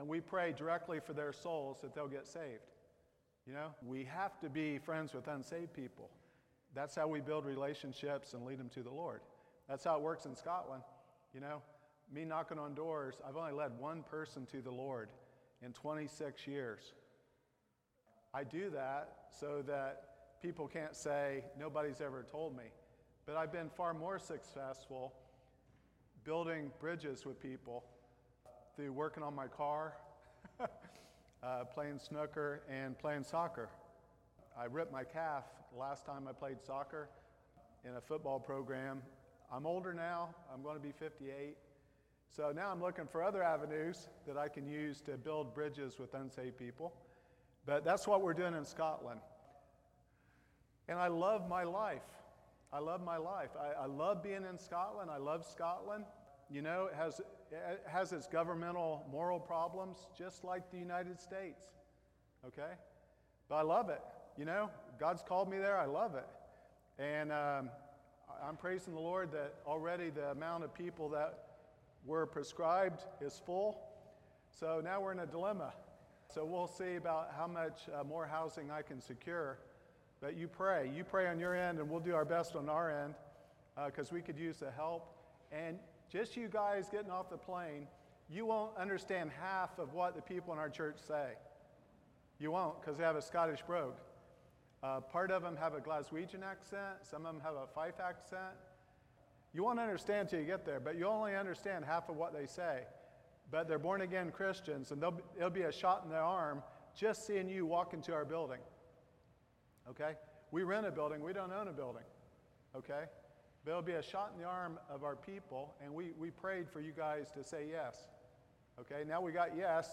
*you may have to turn your volume up for the video, apologies if there is any inconvenience*
Service Type: Sunday Evening